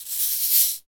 Index of /90_sSampleCDs/Roland L-CD701/PRC_Latin 2/PRC_Shakers